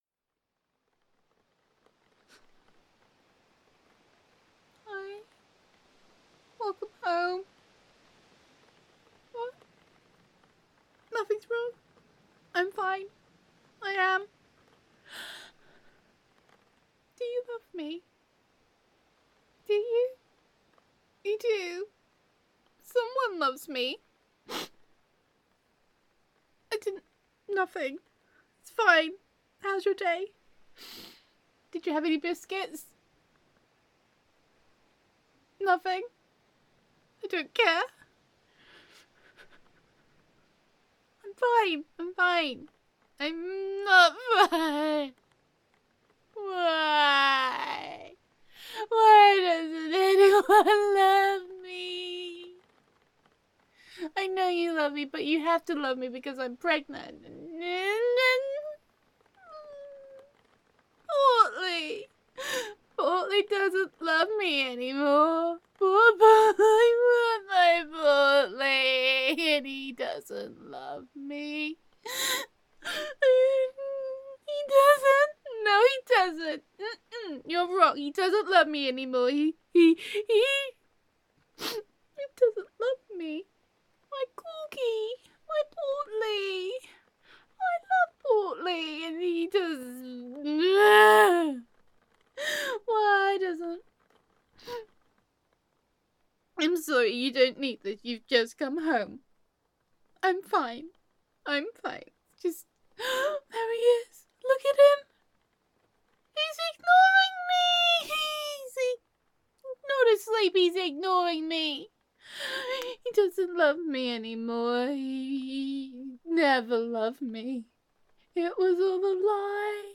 There is a pregnant woman at work and she is super dramatic, and apparently audio inspiring! Watch your ears because pregnant Honey gets hysterical.
Download [F4A] Risky Biscuits [Calling a Dog Portly Is Setting Him up to Be Tubby][Biscuit Addiction][Pregnancy Cravings for Oreos][Sharing Biscuits Is Love]][Overacting][Hysterics][Fried Chicken Makes Everything Better][Gender Neutral].mp3